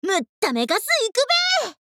贡献 ） 协议：Copyright，其他分类： 分类:雪之美人语音 您不可以覆盖此文件。